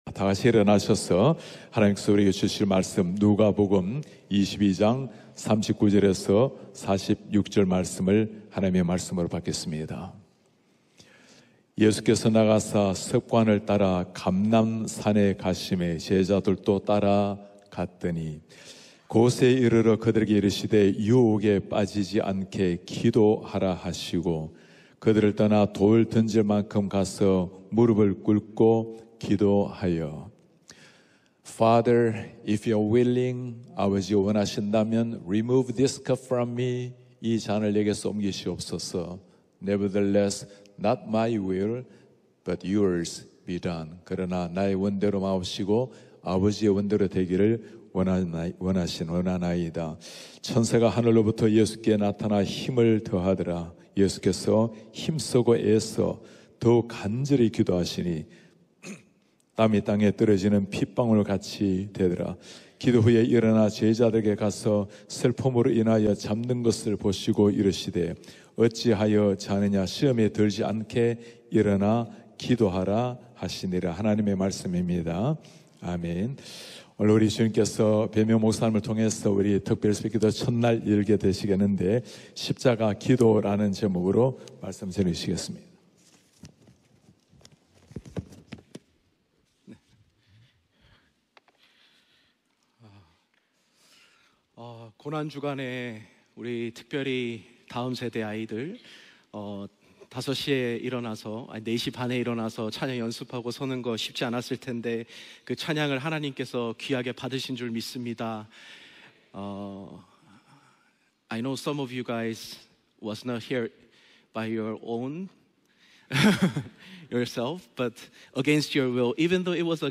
예배: 특별 집회